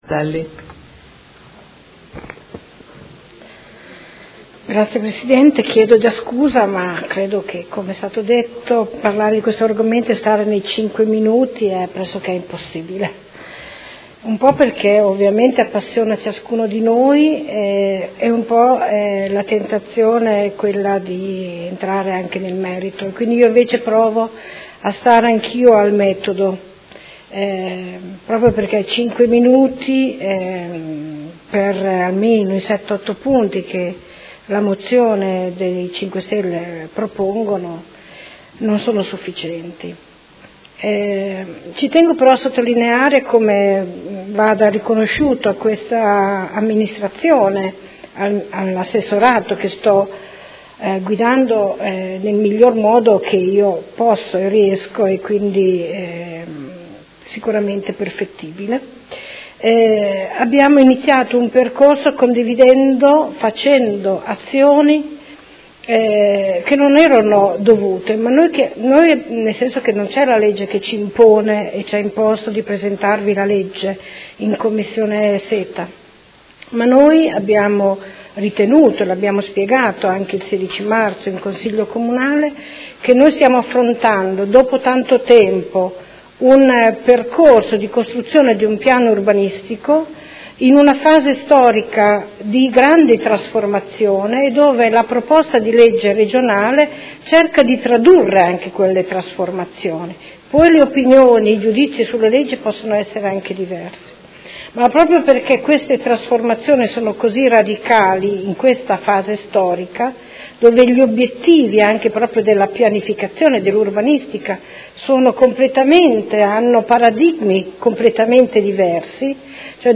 Seduta del 04/05/2017. Dibattito su Ordine del Giorno e Mozione sulla nuova legge urbanistica regionale